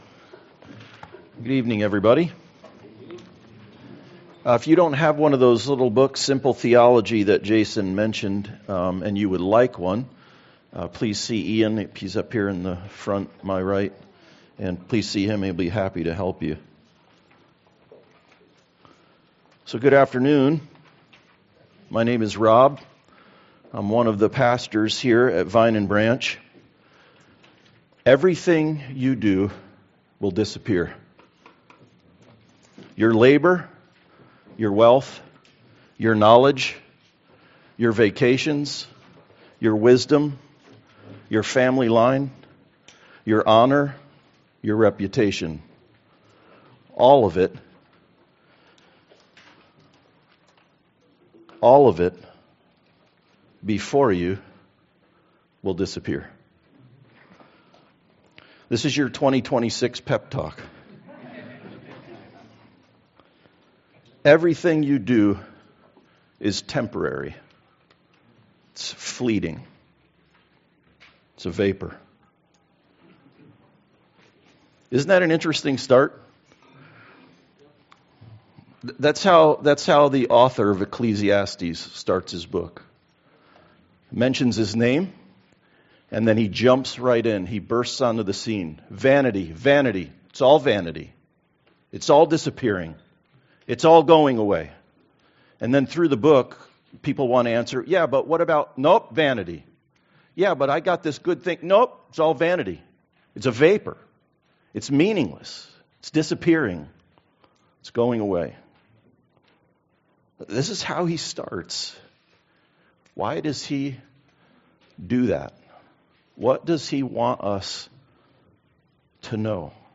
Ecclesiastes 1:1-14 Service Type: Sunday Service All is vanity under the sun outside of Christ.